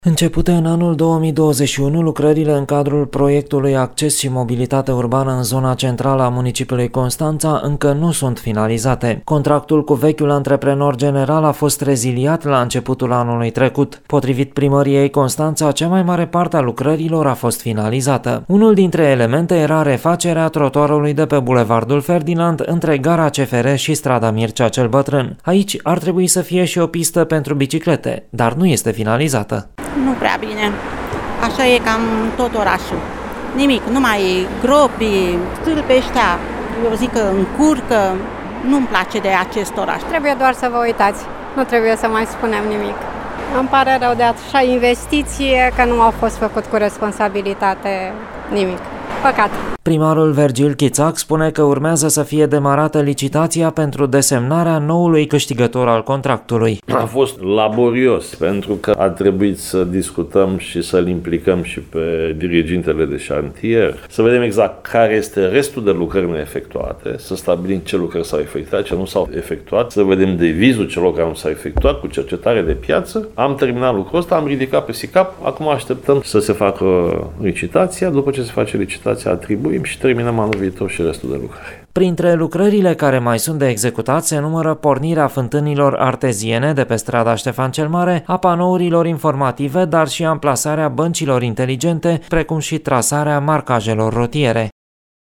Primarul Vergil Chițac a explicat că procedura a fost una complexă, fiind necesară implicarea dirigintelui de șantier pentru a evalua exact stadiul lucrărilor.